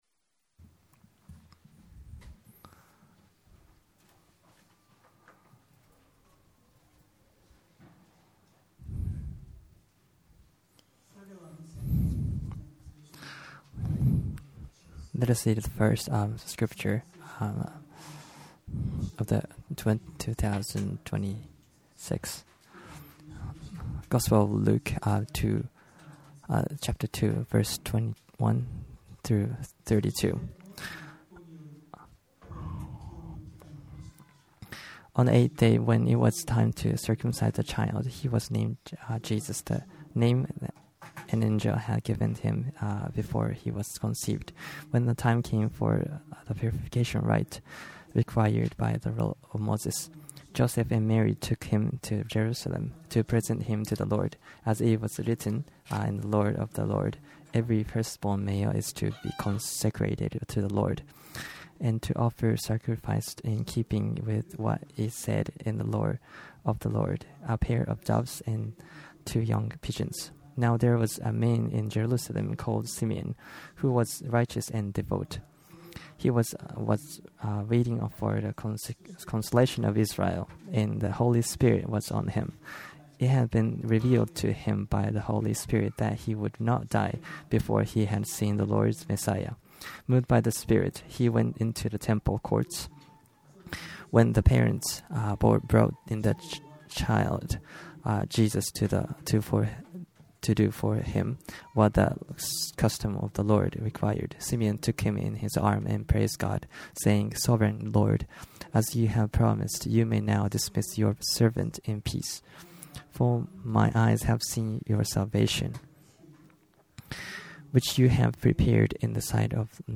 Sermons of Minemachi Christ Church.MCC delivers translated sermons from the Sunday service.